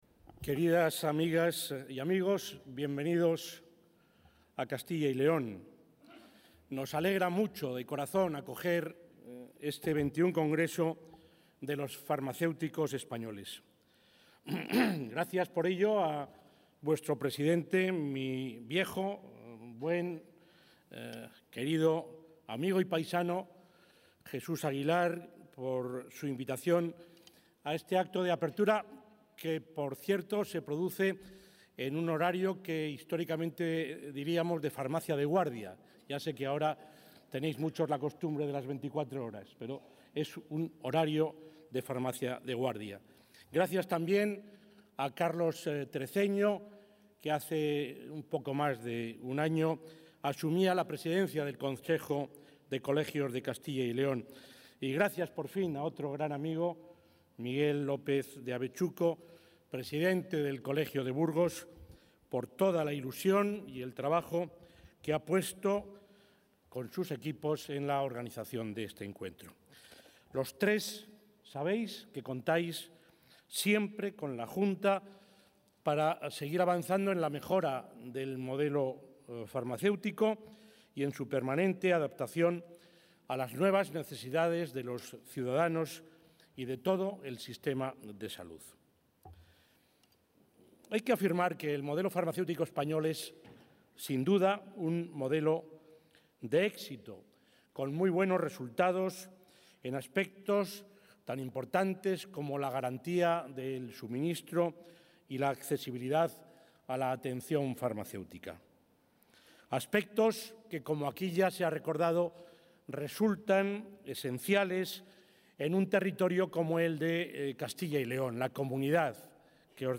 Durante la inauguración del XXI Congreso Nacional de Farmacéuticos, que se celebra estos días en Burgos, el presidente de la...
El presidente de la Junta de Castilla y León, Juan Vicente Herrera, ha inaugurado esta tarde el XXI Congreso Nacional Farmacéutico, que se celebra estos días en la capital burgalesa, donde ha destacado que el sistema farmacéutico español es un modelo de éxito, pero que, como todo servicio, debe contar con una financiación suficiente, que valore el coste real de su prestación, algo que también debe ser tenido en cuenta en el futuro sistema de financiación autonómica.